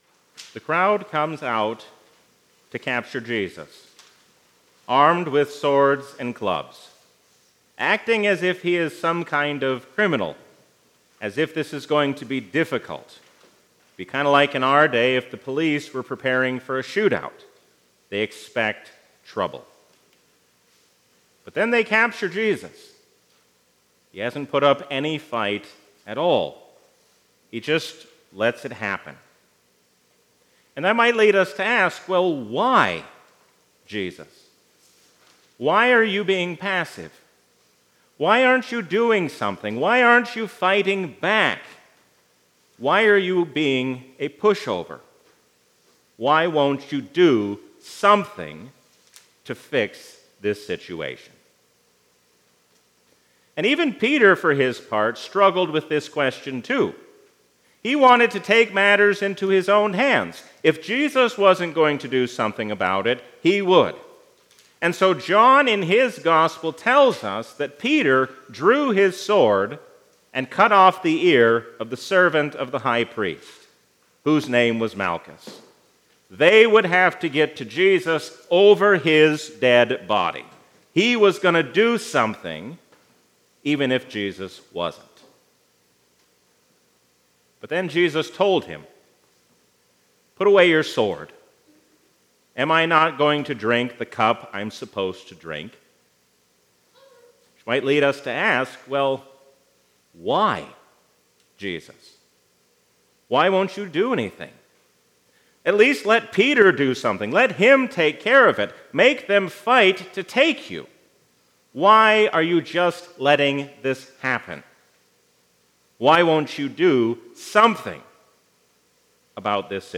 A sermon from the season "Lent 2022." Let us love, think, and act as one, because we are one in Christ.